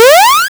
checkpoint.wav